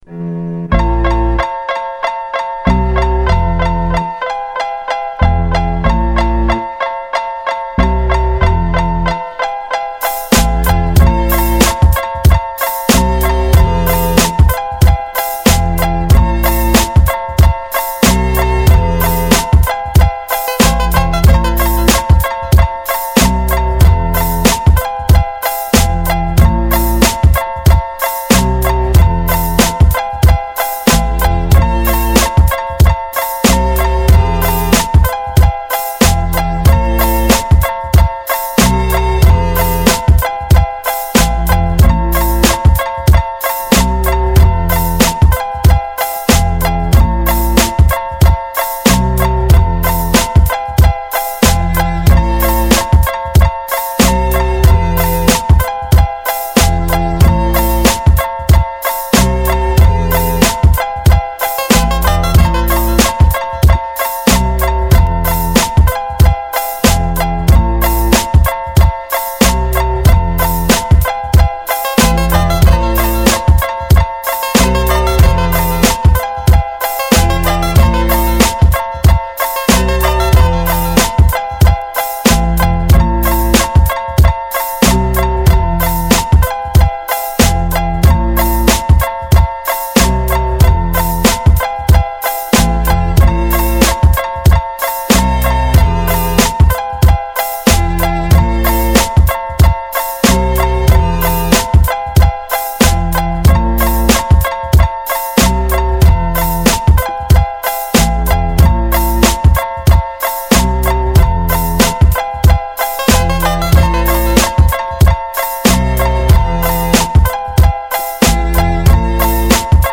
(instrumentaal)